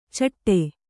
♪ caṭṭe